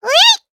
Taily-Vox_Attack1_jp.wav